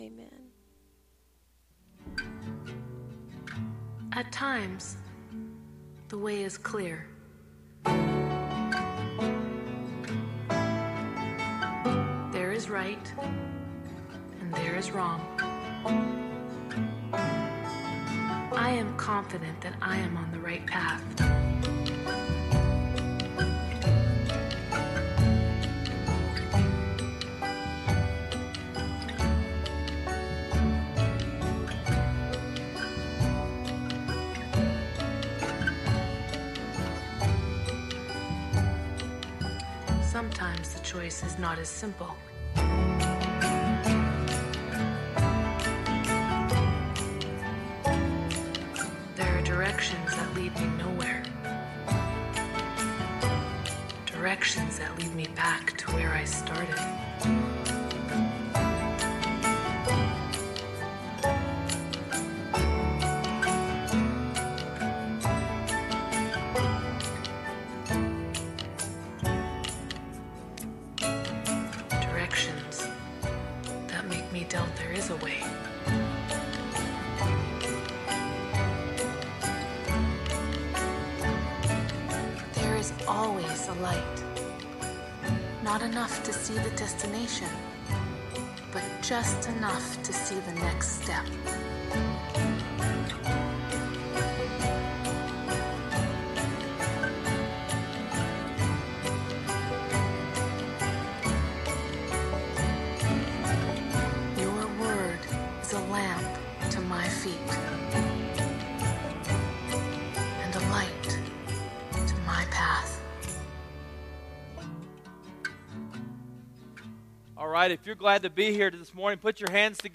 Never Give Up: Finding Strength Through Our Weaknesses: 2 Corinthians 12:7-10 – Sermon Sidekick